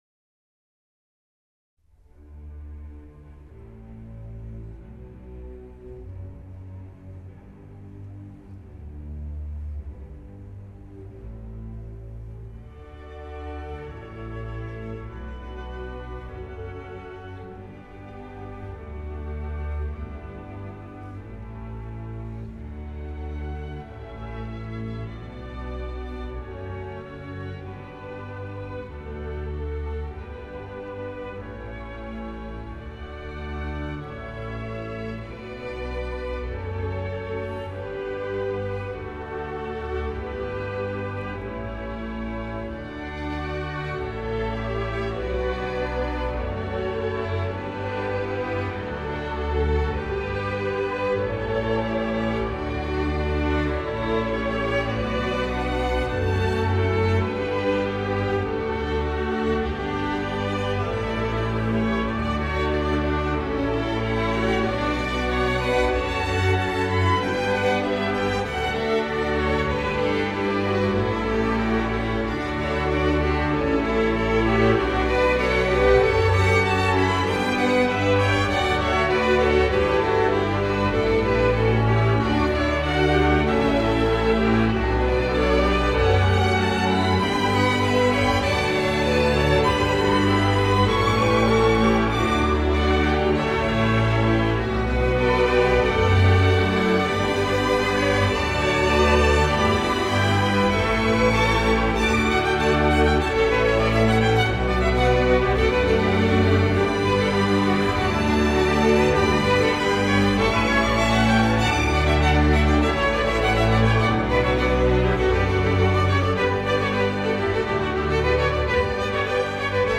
• حس اصالت و شکوه